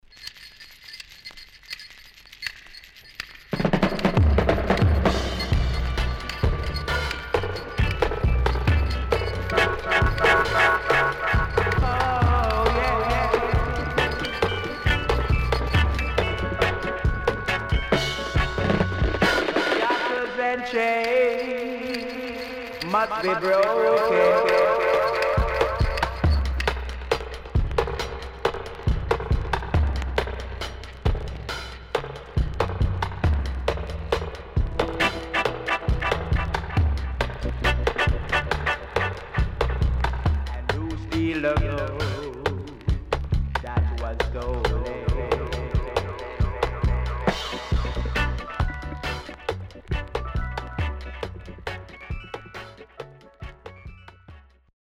77年 Killer Stepper Roots & Dubwise
SIDE A:所々ノイズ入ります。